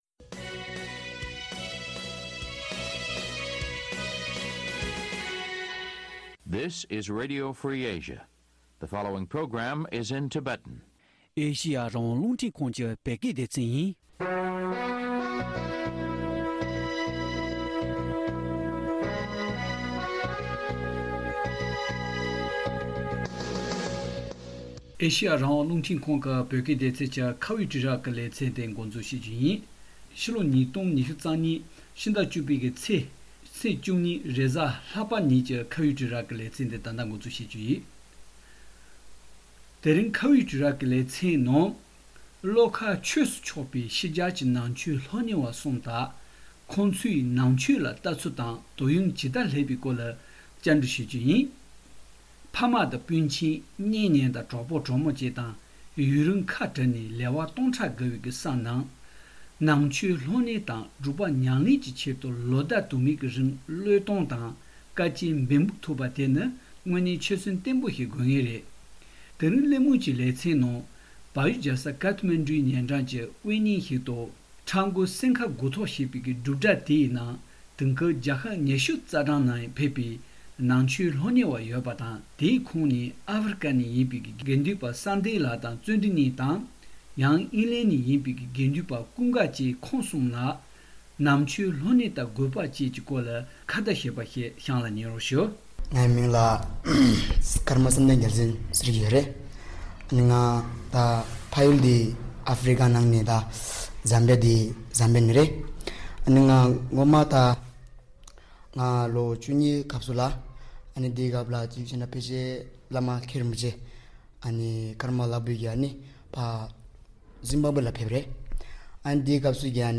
བློ་ཁ་ཆོས་སུ་ཕྱོགས་པའི་ཕྱི་རྒྱལ་གྱི་ནང་ཆོས་སློབ་གཉེར་བ་གསུམ་དང་ལྷན་ནང་ཆོས་ལ་བལྟ་ཚུལ་དང་དོ་སྣང ཇི་ལྟར་སླེབས་པའི་སྐོར་བགྲོ་གླེང་ཞུས་པར་གསན་རོགས།